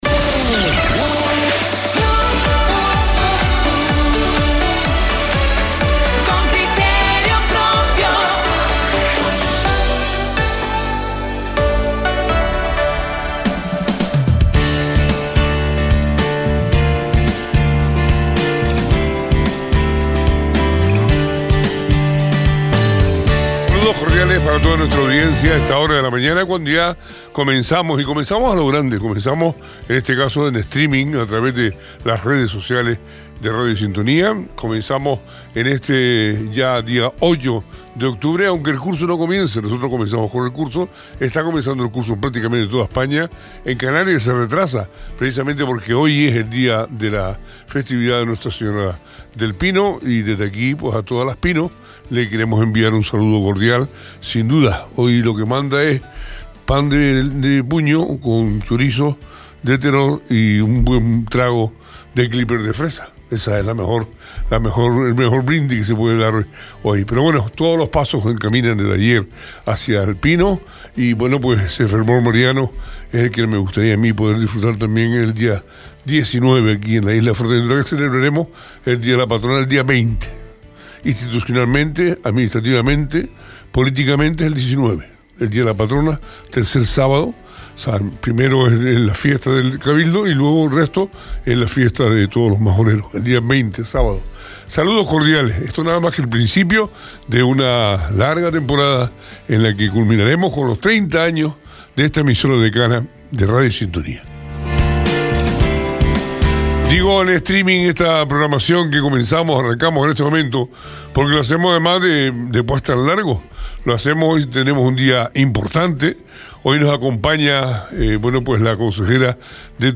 Jessica de León, consejera de Turismo y Empleo del Gobierno de Canarias es entrevistada en Radio Sintonía - Radio Sintonía
Entrevistas